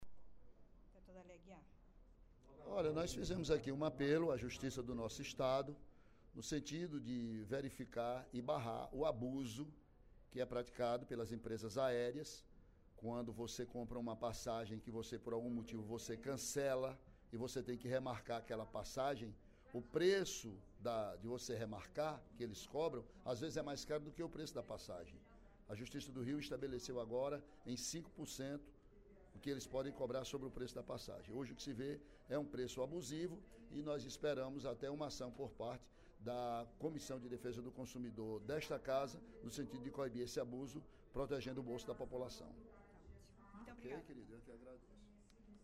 A cobrança de taxas abusivas para o cancelamento com reembolso ou remarcação de passagens aéreas foi criticada, nesta sexta-feira (14/08), pelo deputado Ely Aguiar (PSDC). Durante o primeiro expediente da sessão plenária da Assembleia Legislativa, o parlamentar fez um apelo à Justiça do Ceará para barrar esse “abuso” praticado pelas empresas aéreas.